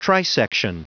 Prononciation du mot trisection en anglais (fichier audio)
trisection.wav